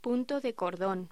Locución: Punto de cordón
voz
Sonidos: Voz humana